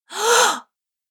mixkit-female-astonished-gasp-964.ogg